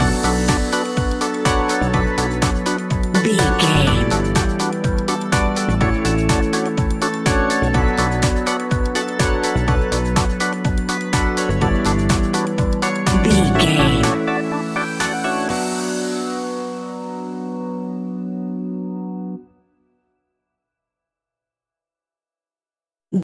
Aeolian/Minor
groovy
hypnotic
uplifting
synthesiser
drum machine
electric guitar
funky house
deep house
nu disco
upbeat
funky guitar
clavinet
synth bass
funky bass